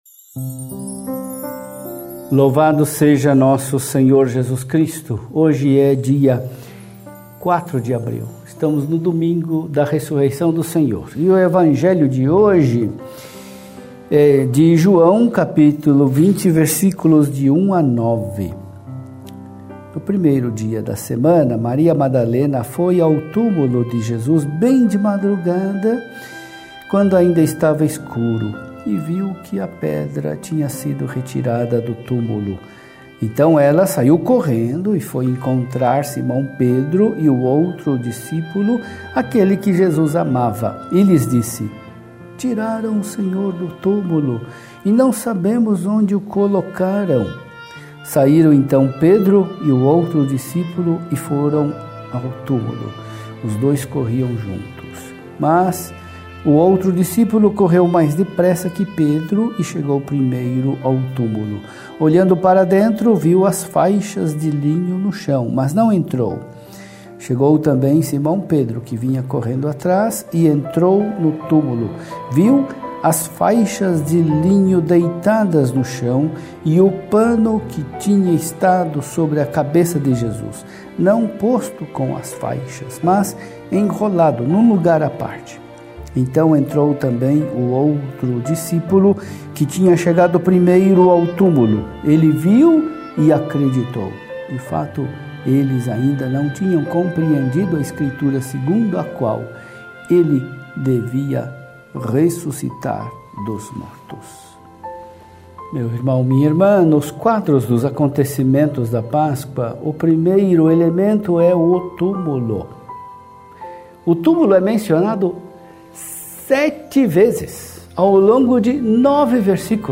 Por Dom Julio Endi Akamine SAC